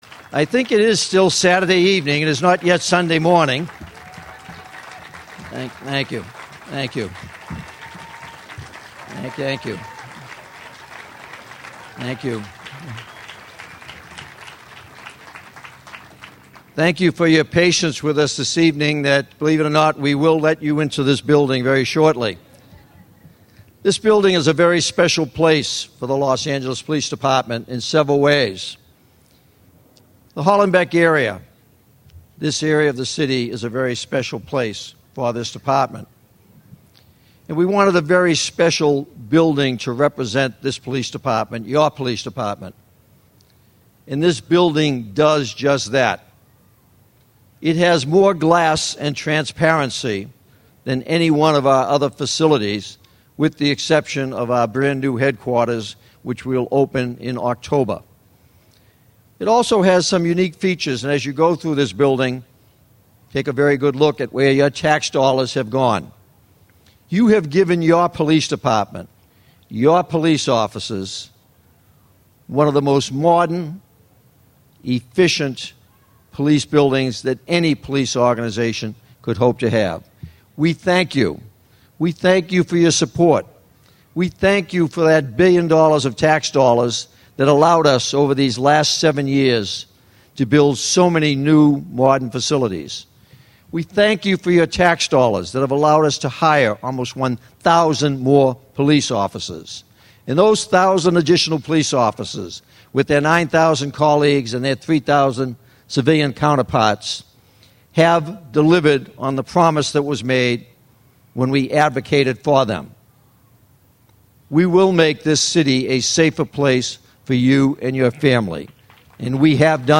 Chief Bratton at Hollenbeck Grand Opening - podcast